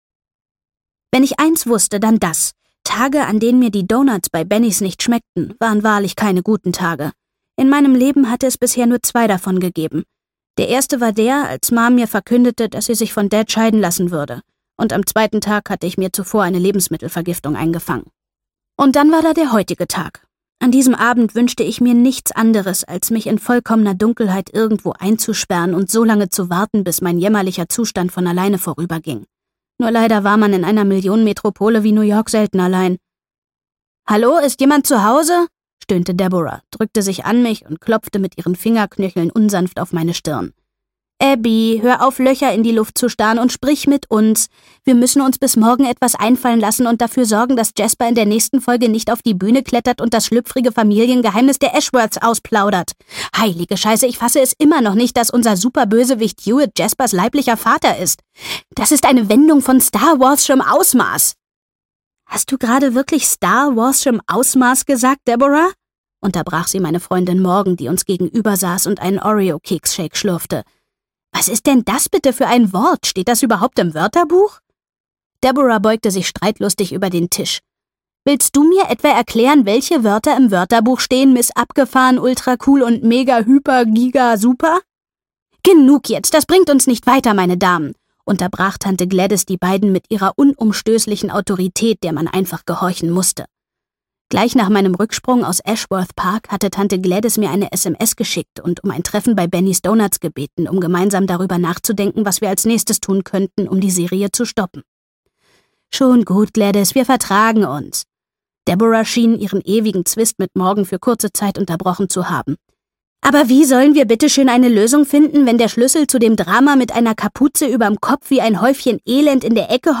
Verliebt in Serie 3: Tulpen und Traumprinzen - Verliebt in Serie, Folge 3 - Sonja Kaiblinger - Hörbuch - Legimi online